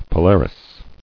[Po·lar·is]